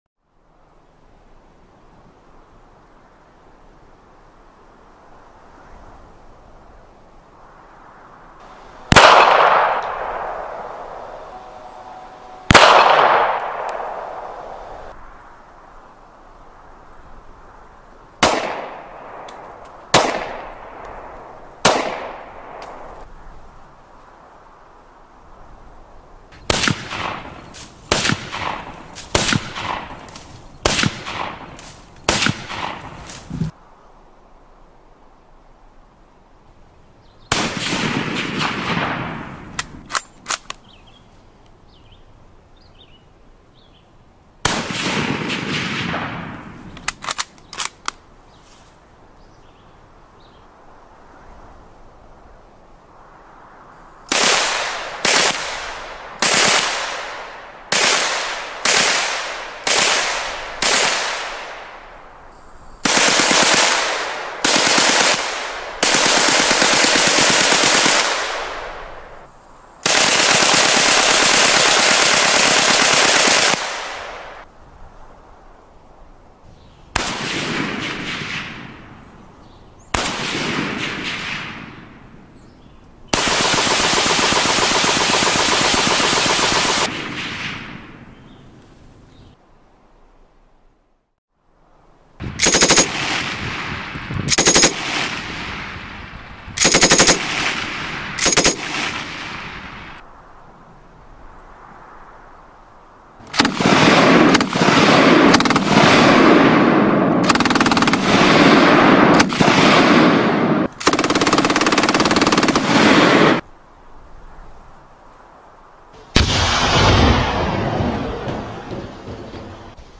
دانلود آهنگ تیراندازی 3 از افکت صوتی اشیاء
دانلود صدای تیراندازی 3 از ساعد نیوز با لینک مستقیم و کیفیت بالا
جلوه های صوتی